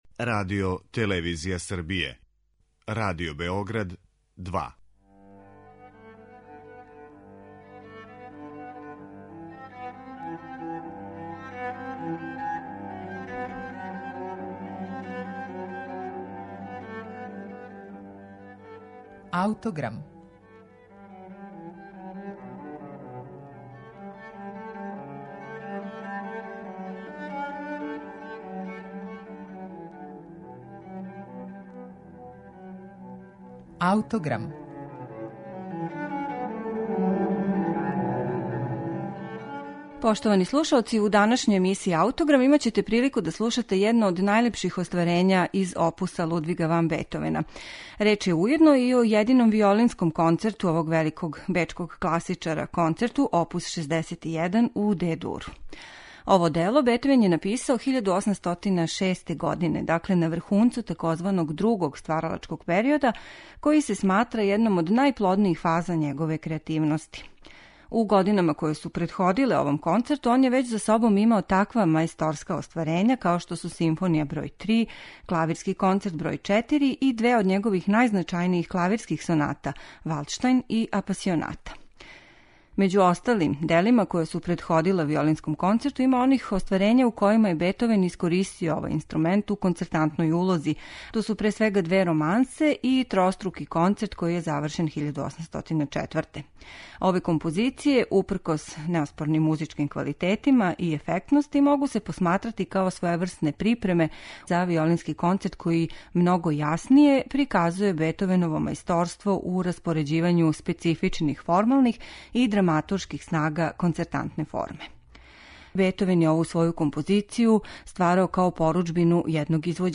Концерту за виолину оп. 61 у Де-дуру